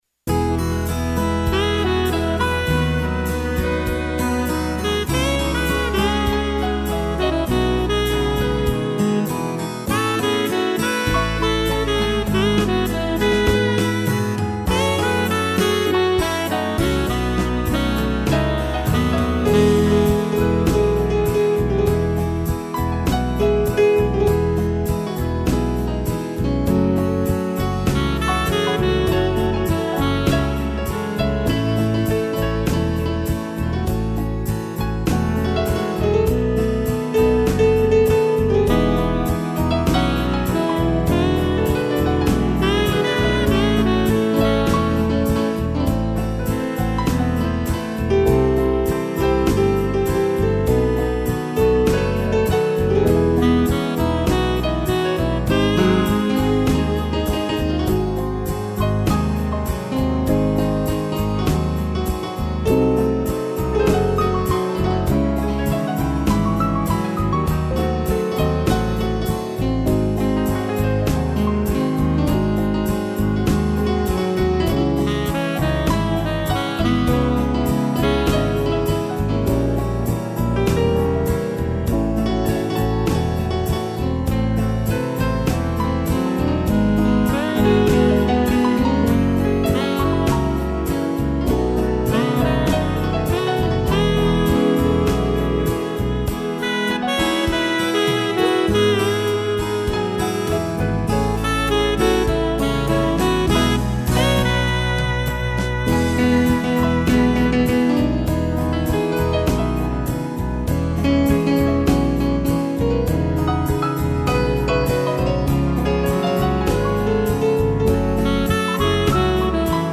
piano, sax e cello
(instrumental)